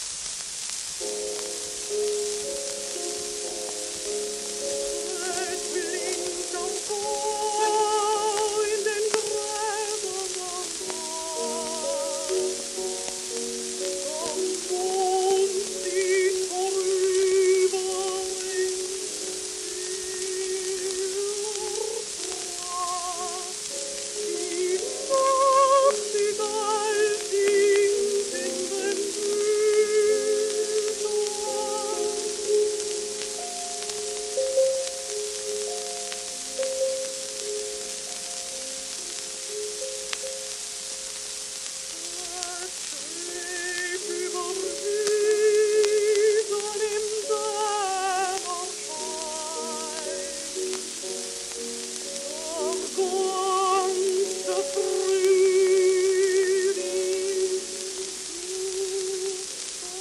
w/ピアノ
旧 旧吹込みの略、電気録音以前の機械式録音盤（ラッパ吹込み）